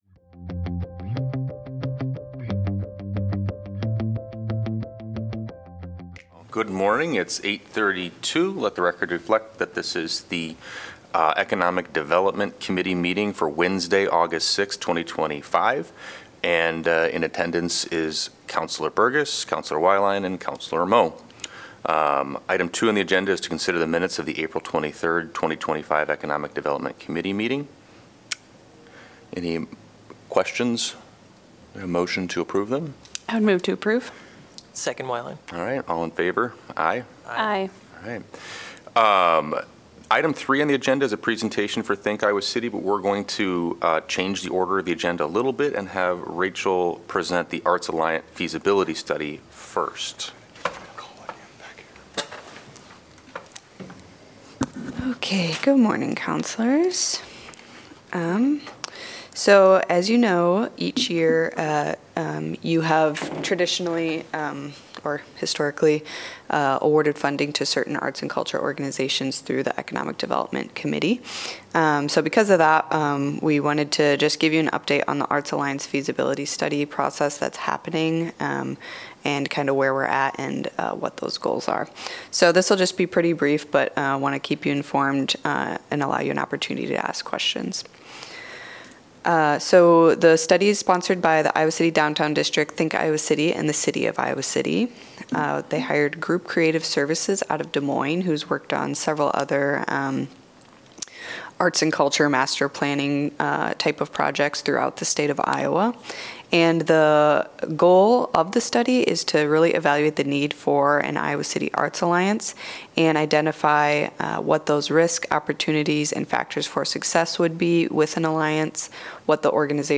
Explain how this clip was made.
A meeting of the Iowa City City Council's Economic Development Committee.